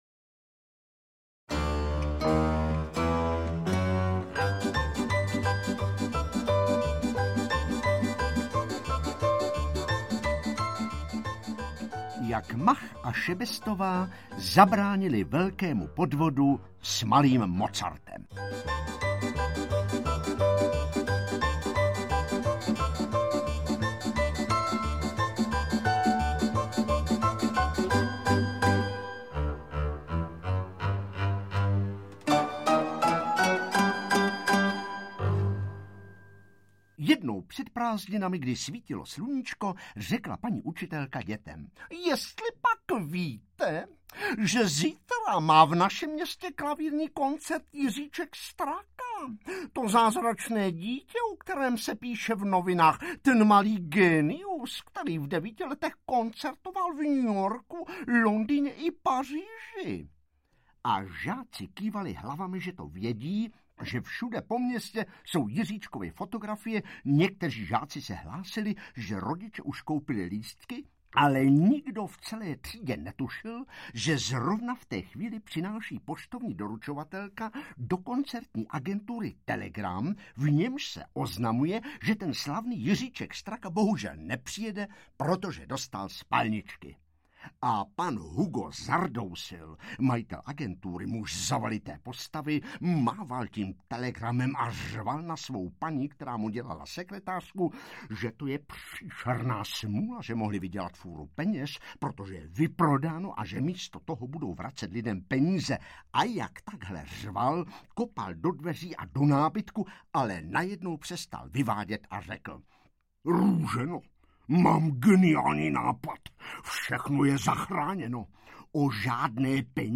Interpret:  Petr Nárožný
AudioKniha ke stažení, 6 x mp3, délka 1 hod. 16 min., velikost 69,1 MB, česky